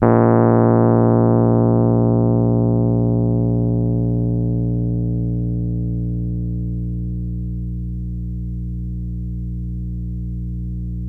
RHODES CL02L.wav